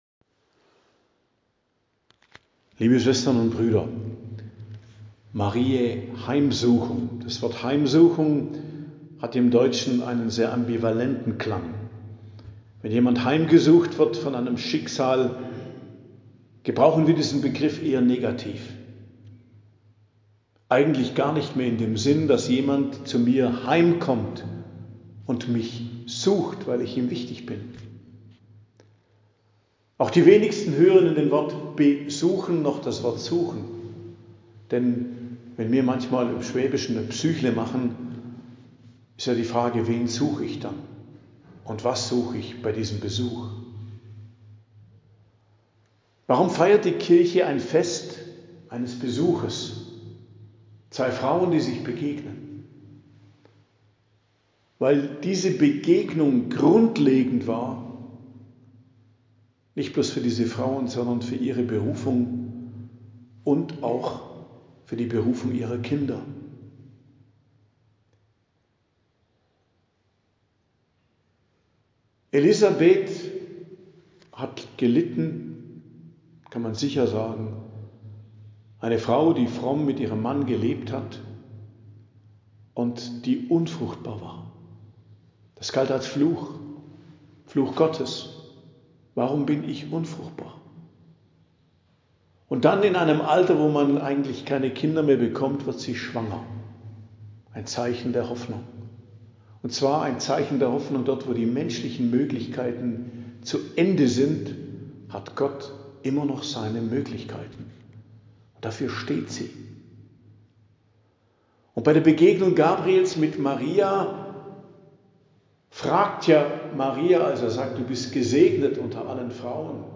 Predigt am Fest Mariä Heimsuchung, 2.07.2024 ~ Geistliches Zentrum Kloster Heiligkreuztal Podcast